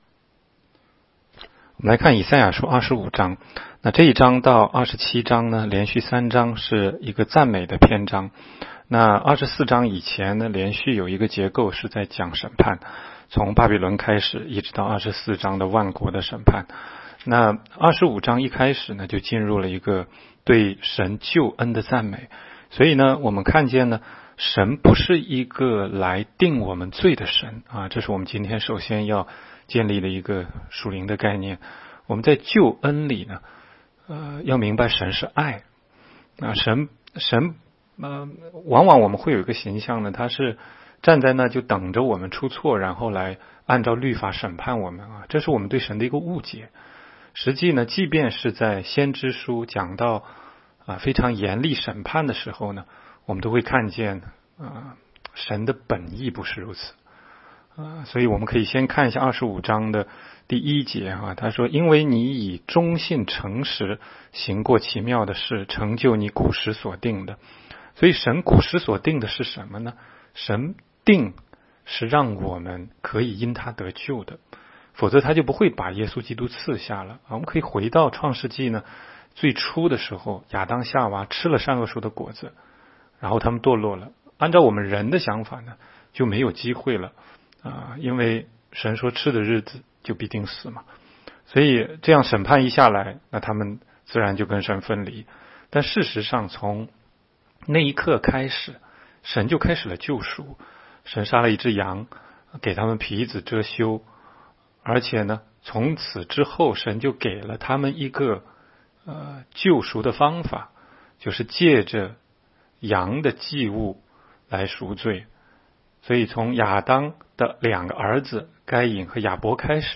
16街讲道录音 - 每日读经 -《 以赛亚书》25章